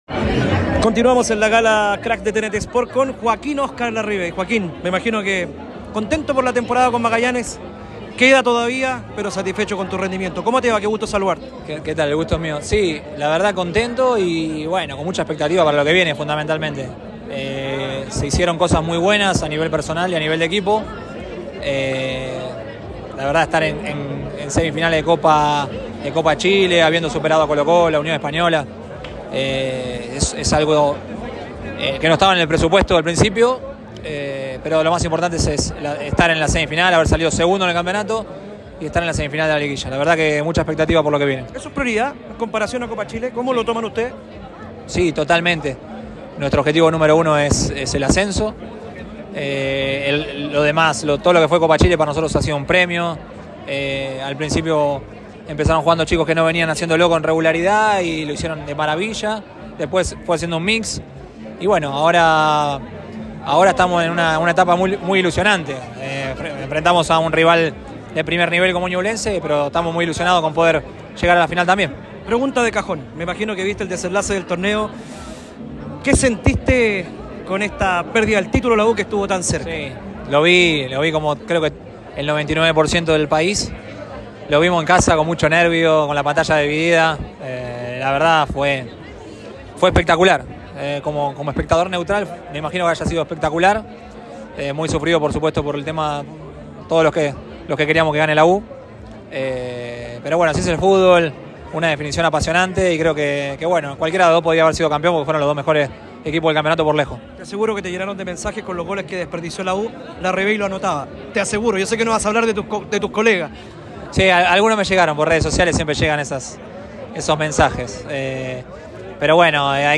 En diálogo con ADN Deportes, el delantero de 40 años valoró su presente con la camiseta de Magallanes y reconoció que estuvo cerca de regresar a los azules a inicios de la temporada.
Joaquín Larrivey, delantero de Magallanes, habló este lunes con ADN Deportes en el marco de la ceremonia de la Gala Crack 2024, donde valoró su actual campaña con la “Academia” en la Primera B y se mostró ilusionado con volver a la U de Chile.